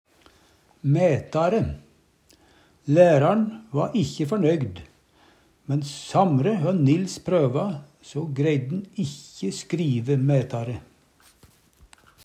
mætare - Numedalsmål (en-US)
DIALEKTORD PÅ NORMERT NORSK mætare betre, duglegare, meir verdigfullt Eksempel på bruk Lærar'n va ikkje førnøygd, men samre hø Nils prøva, so greidden ikkje skrive mætare.